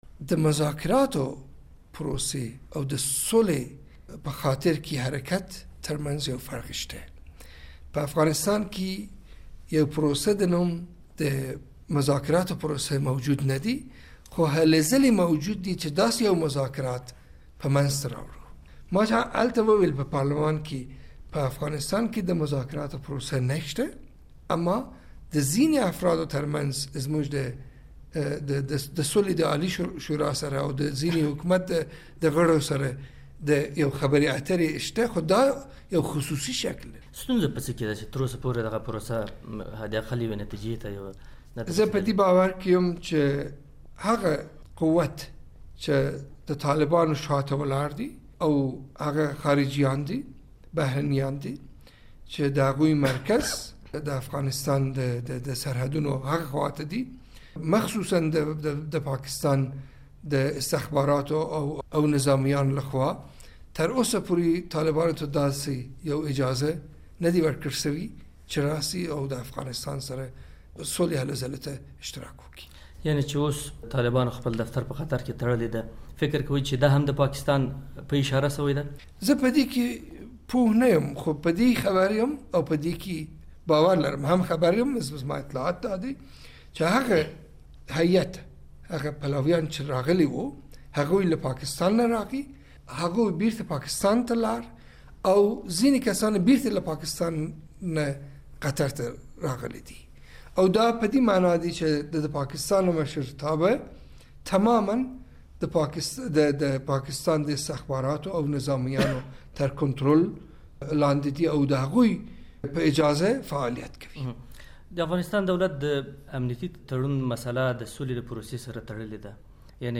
له رنګين دادفر سپنتا سره مرکه